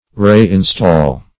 Meaning of re-install. re-install synonyms, pronunciation, spelling and more from Free Dictionary.
re-install.mp3